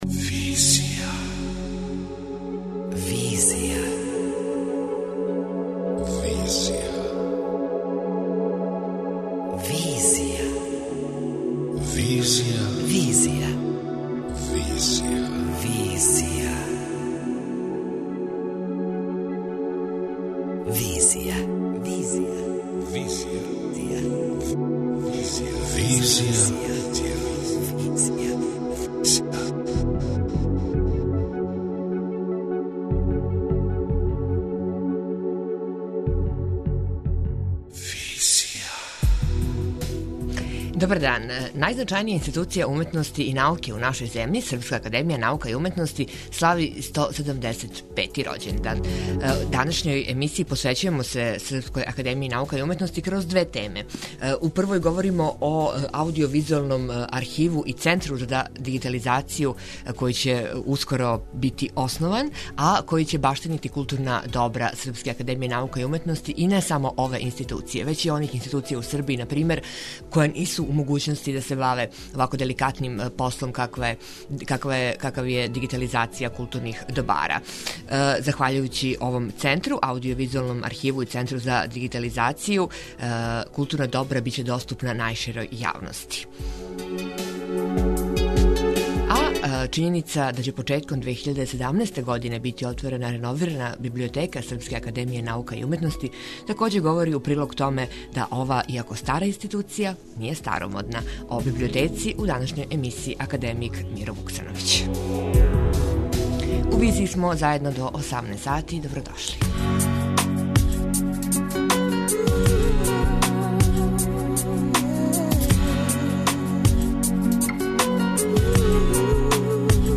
преузми : 27.20 MB Визија Autor: Београд 202 Социо-културолошки магазин, који прати савремене друштвене феномене.